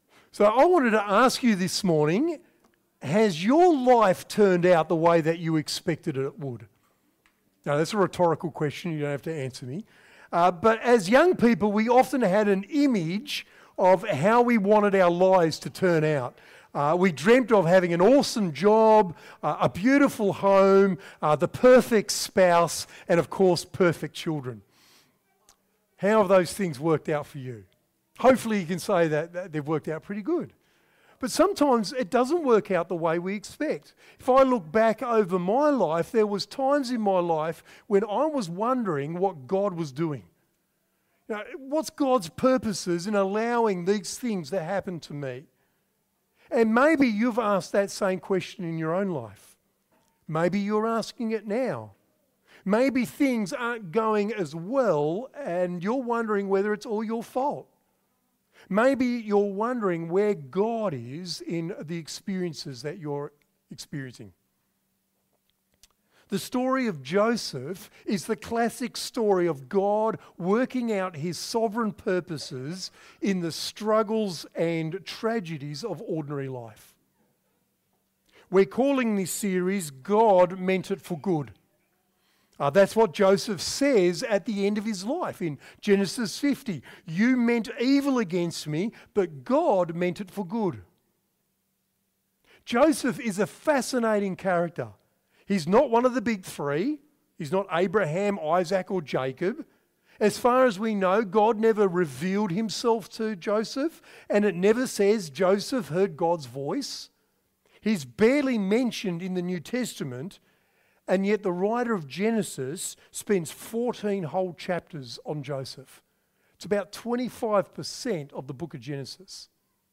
A sermon from Genesis 37 about Joseph's birth, his life as a young man, his dreams, and just how bad things can get.